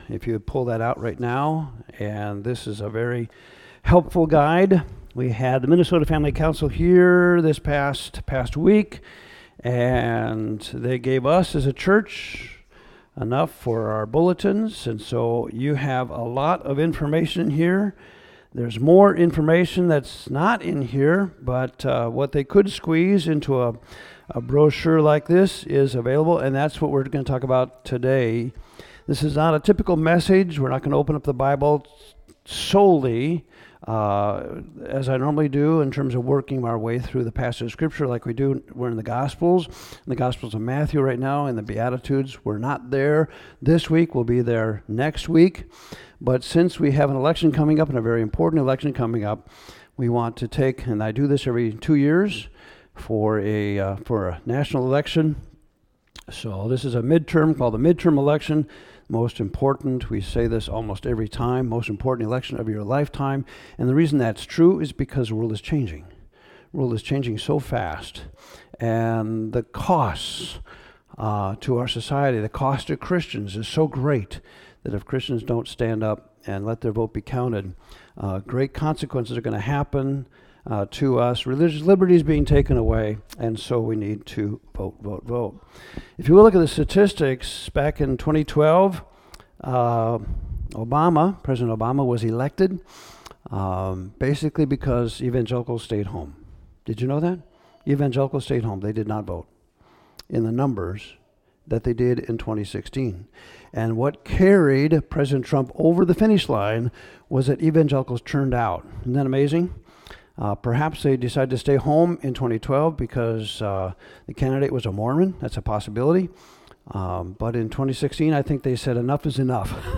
Cornerstone Church Audio Sermons